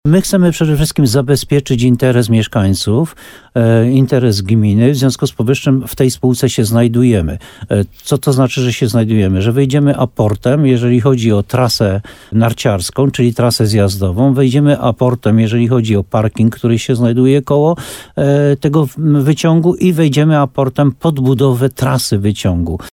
Jak poinformował w porannej rozmowie RDN Nowy Sącz burmistrz uzdrowiska Jan Golba, to da gminie możliwości, jakich do tej pory nie miała.